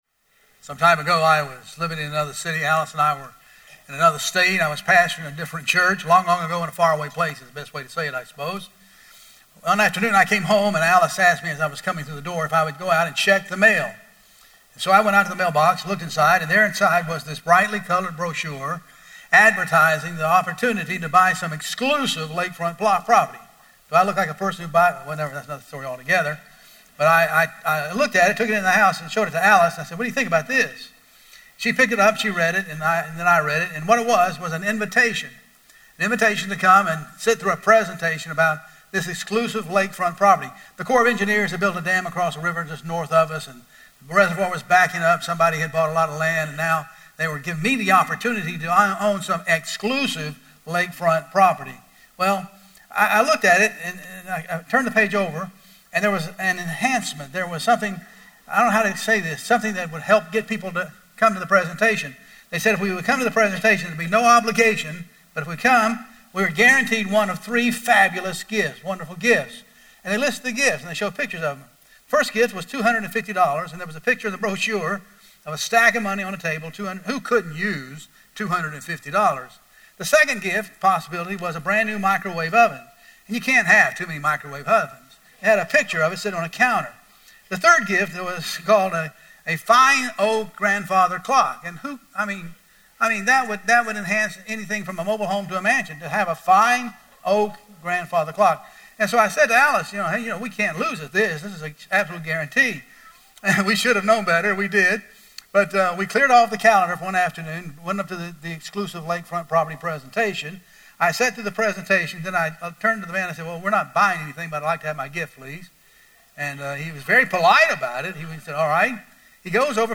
Historical Message Archives The Historical Message Archives includes weekly Sunday morning messages from August 1992 – August 2018.